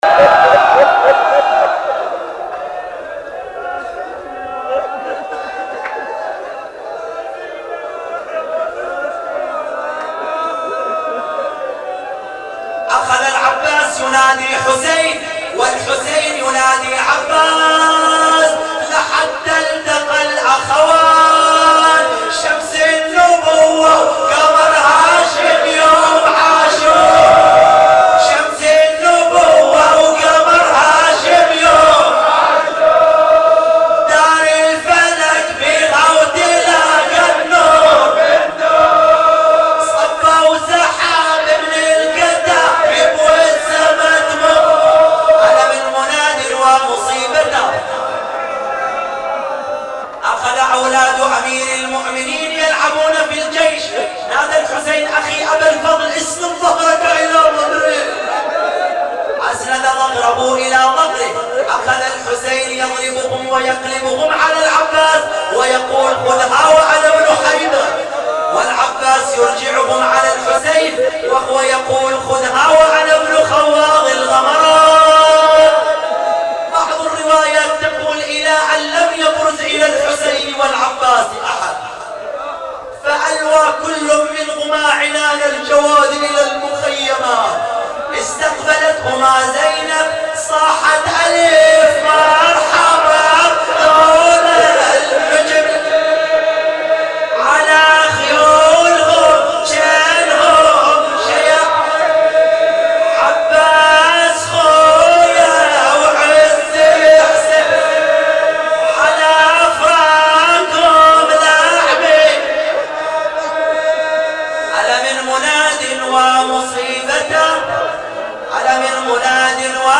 نواعي حسينية15